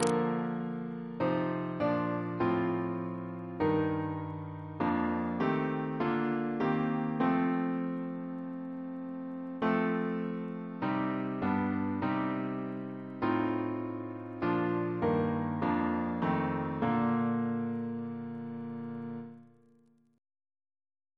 Double chant in B♭ Composer: Thomas Attwood (1765-1838), Organist of St. Paul's Cathedral Reference psalters: ACB: 199; ACP: 251; H1982: S192 S284; OCB: 42